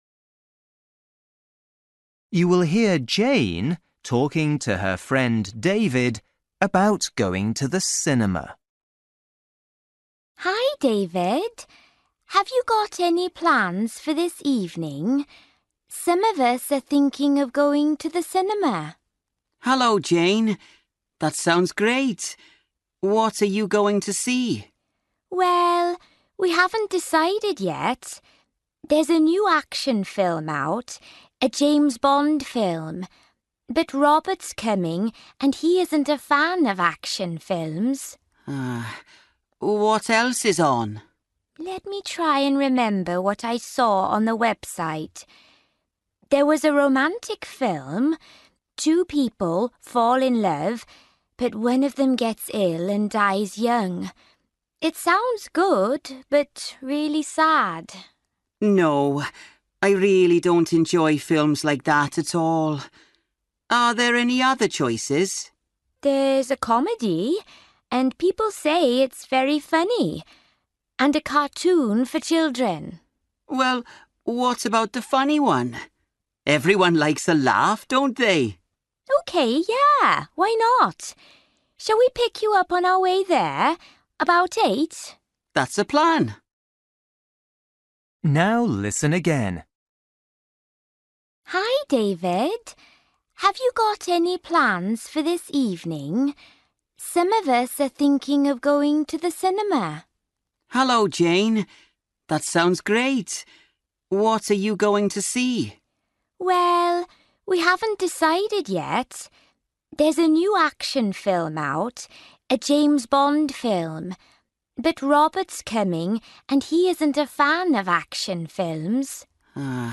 Bài tập trắc nghiệm luyện nghe tiếng Anh trình độ sơ trung cấp – Nghe một cuộc trò chuyện dài phần 7